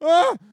scream_short_1.ogg